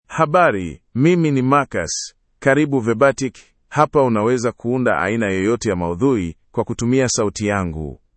MaleSwahili (Kenya)
MarcusMale Swahili AI voice
Marcus is a male AI voice for Swahili (Kenya).
Voice sample
Marcus delivers clear pronunciation with authentic Kenya Swahili intonation, making your content sound professionally produced.